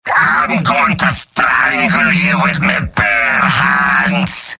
Robot-filtered lines from MvM. This is an audio clip from the game Team Fortress 2 .